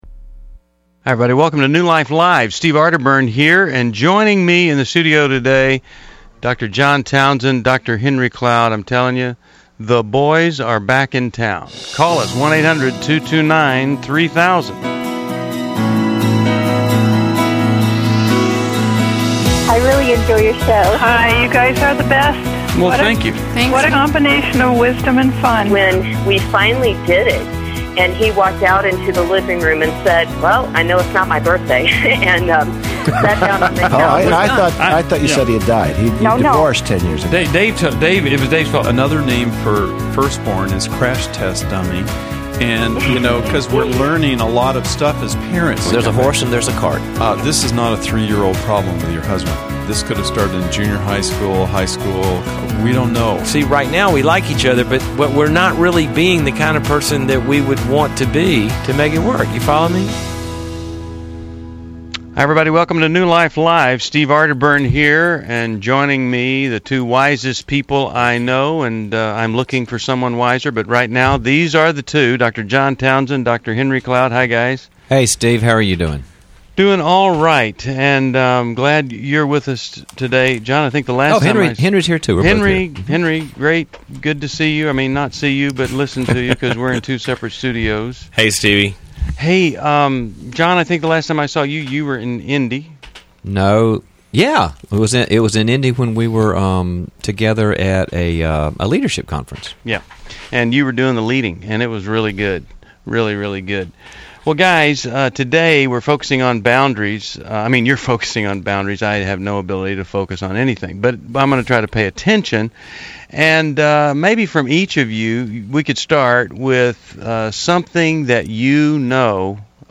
Explore boundaries in family, dating, and marriage on New Life Live: November 18, 2011, as experts tackle real caller concerns about sexual integrity and relationships.